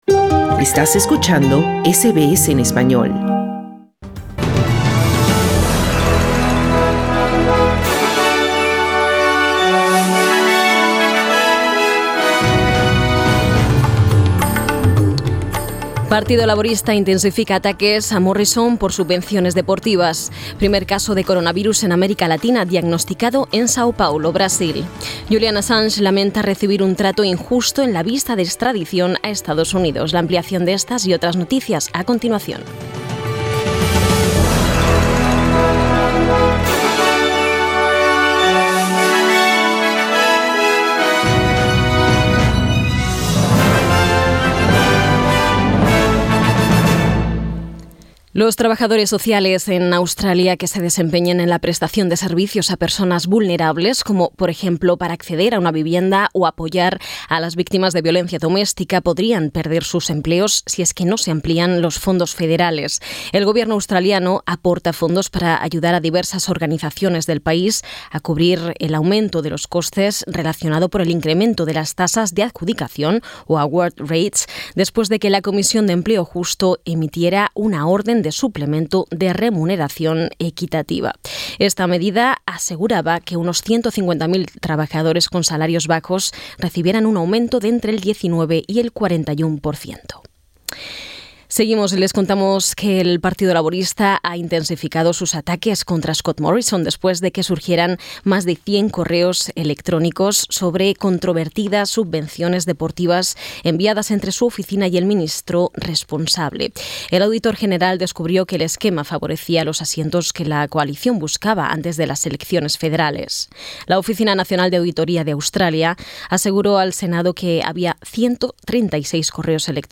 Noticias SBS Spanish | 27 febrero 2020